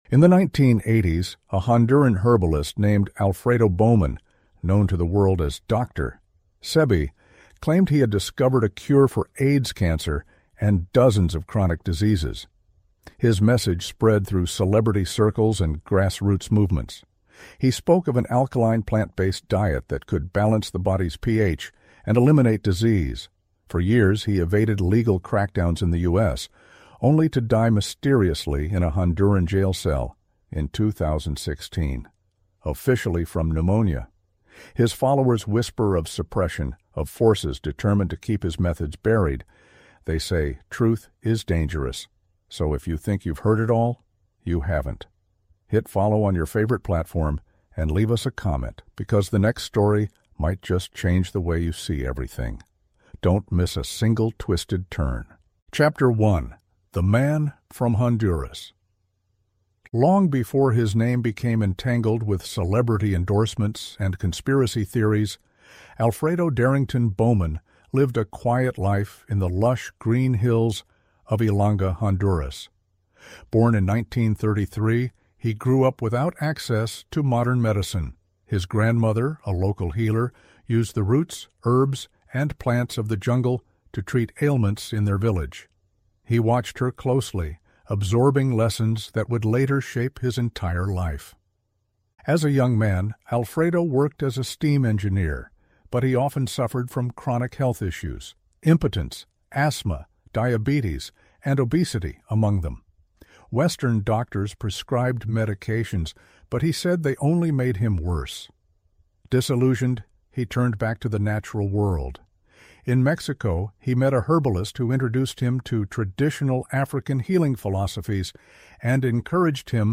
This gripping 7-chapter audio documentary explores the extraordinary life and mysterious death of Dr. Sebi, the Honduran herbalist who claimed to reverse deadly diseases with a natural, alkaline plant-based lifestyle. We trace his legal battles, his legendary healing village in Honduras, and the global movement sparked by celebrities like Left Eye and Nipsey Hussle.